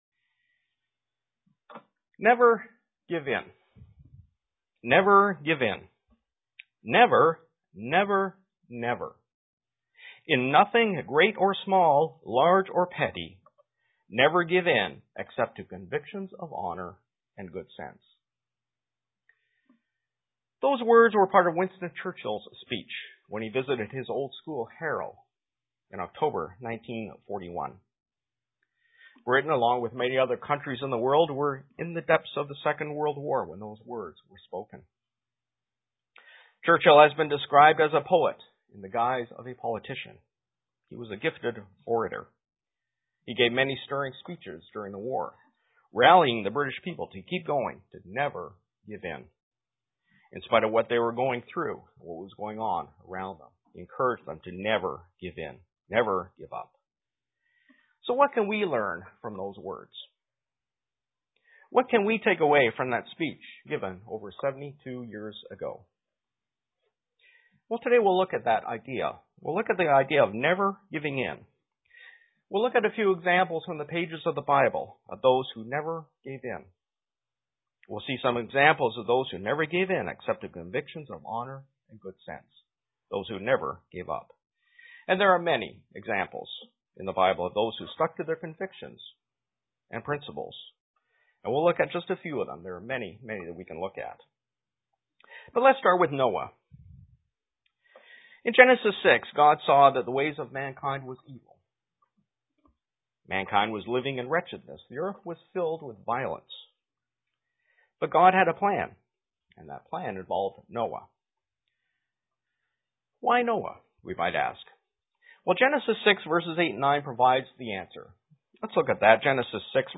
Print Never give in and examples from the Bible of those who did not give in UCG Sermon Studying the bible?
Given in Elmira, NY